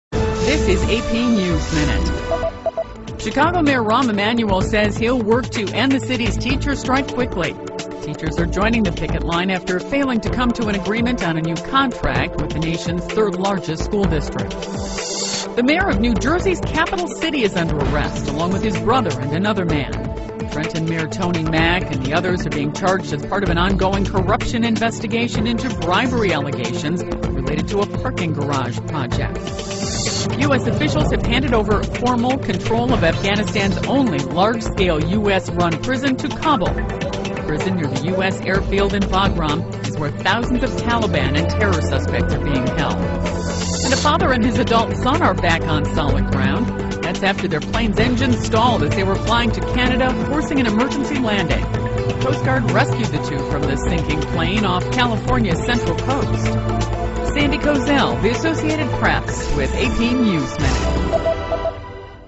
在线英语听力室美联社新闻一分钟 AP 2012-09-13的听力文件下载,美联社新闻一分钟2012,英语听力,英语新闻,英语MP3 由美联社编辑的一分钟国际电视新闻，报道每天发生的重大国际事件。电视新闻片长一分钟，一般包括五个小段，简明扼要，语言规范，便于大家快速了解世界大事。